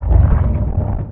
walk_act.wav